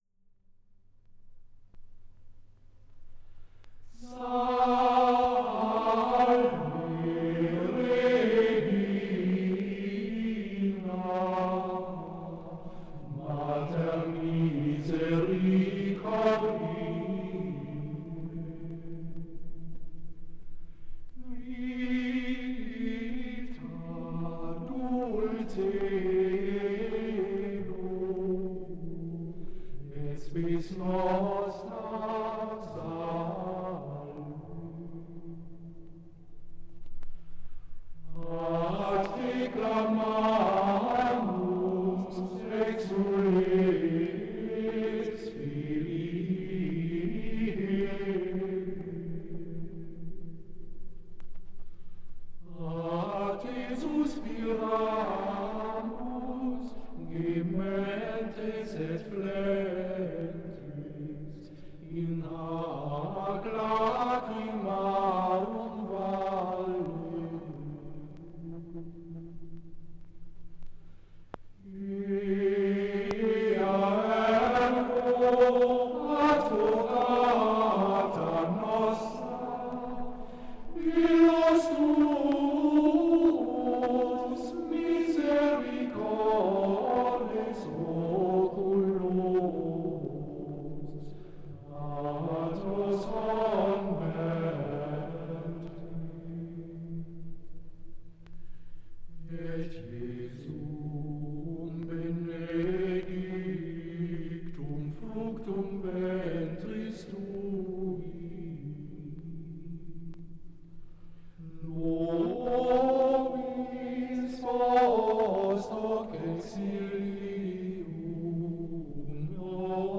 Marianische Antiphone
Gesang: Schola gregoriana im Auftrag der Robert-Schumann-Hochschule Düsseldorf
aufgenommen in der Klosterkirche Knechtsteden